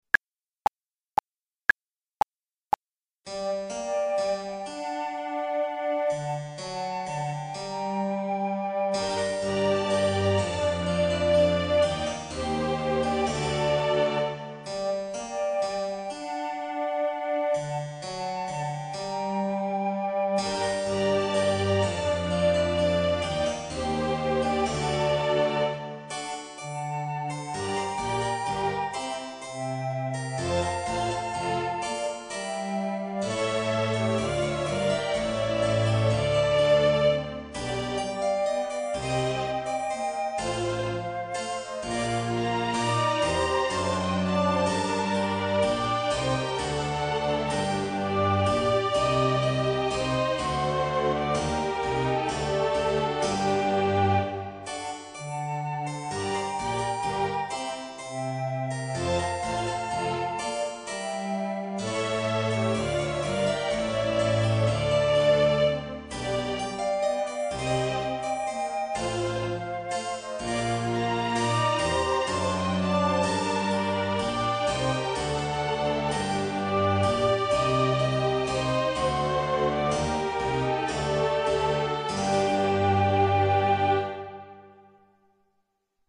E' questo il titolo del Concerto Grosso di A. Corelli di cui proponiamo il "Vivace" a due flauti.